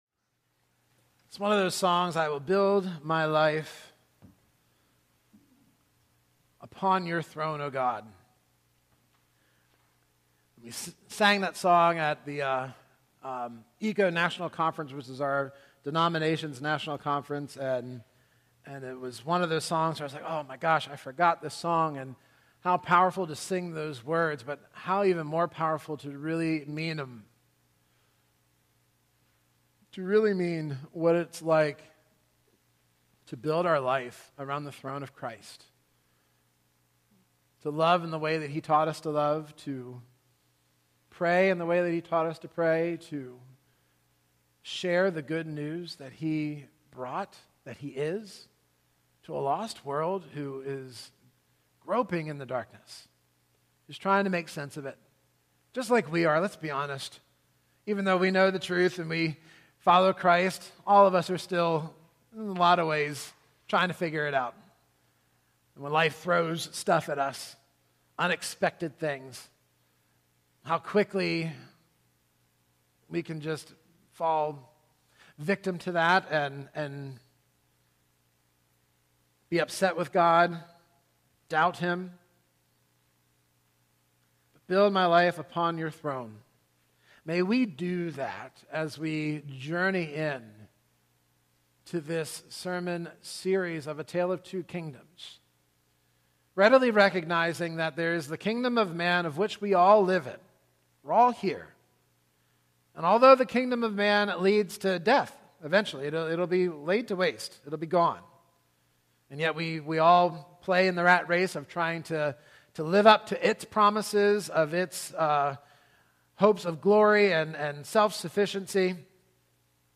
In this sermon series, we will explore Jesus’ Kingdom parables to learn what this new citizenship means for our faith, our loyalties, and our daily lives, and how Christ sends us into the world to bear witness to His Kingdom—freeing us from the idols, false promises, and misplaced hopes of the kingdom of man.